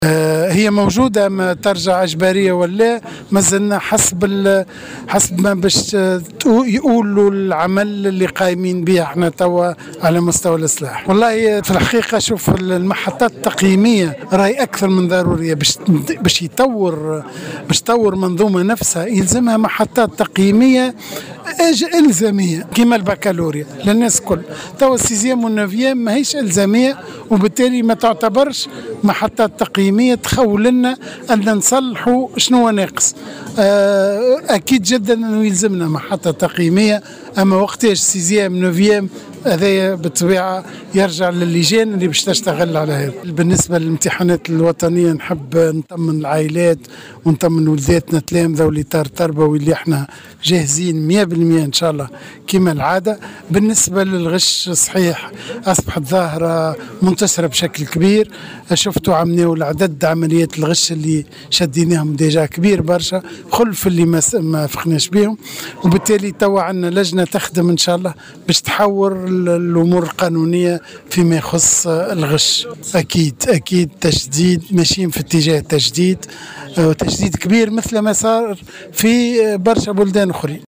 قال وزير التربية فتحي السلاوتي في تصريح لمراسل الجوهرة أف أم، إن الوزارة على أتم الاستعداد لتنظيم الامتحانات الوطنية في أفضل الظروف.